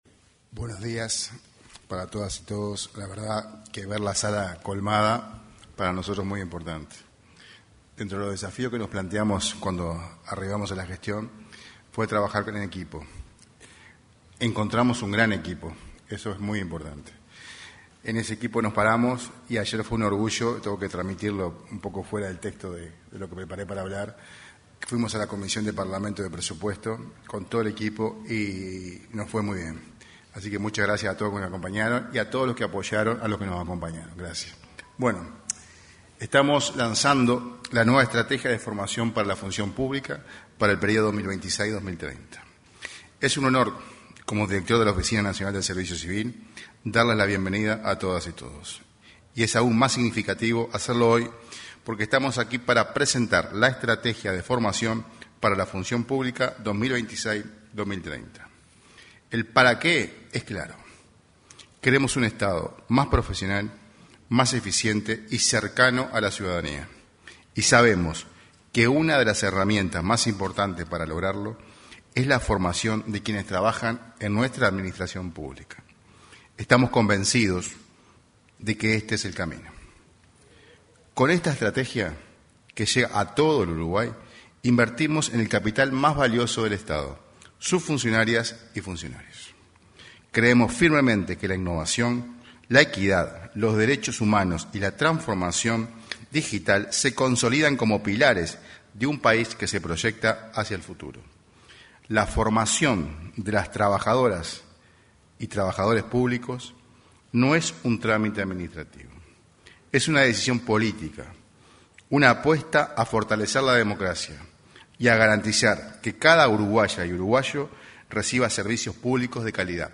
Se realizó la presentación de la Estrategia de Formación para la Función Pública 2026-2030.
En la oportunidad, se expresaron el director de la Oficina Nacional del Servicio Civil, Sergio Pérez; el director de la Escuela Nacional de Administración Pública, Bruno Minchilli; la titular de la Secretaría de Derechos Humanos de Presidencia, Colette Spinetti, y la ministra de Industria, Energía y Minería, Fernanda Cardona.